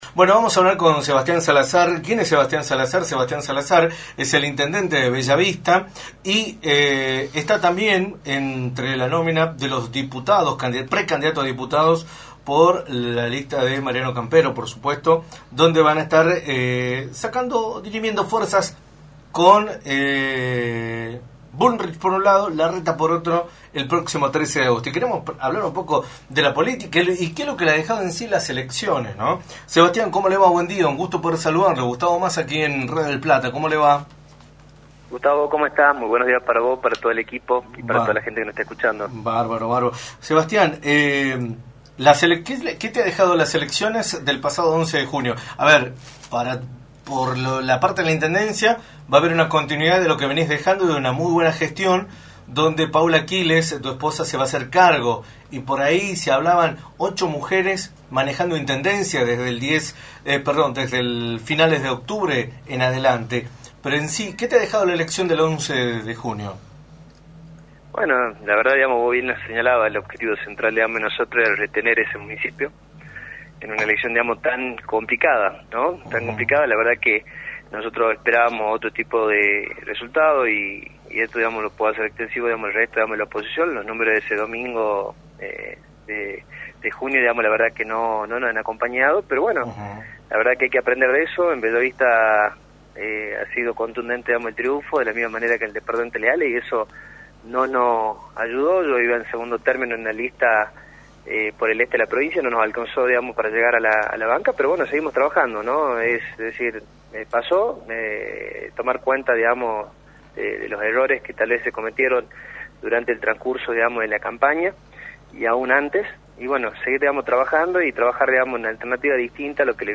Sebastián Salazar, Intendente de Bella Vista y precandidato a Diputado en la lista de Juntos por el Cambio que encabeza Patricia Bullrich a nivel nacional y Mariano Campero en Tucumán, analizó en Radio del Plata Tucumán, por la 93.9, el resultado de las elecciones provinciales y la previa de las PASO.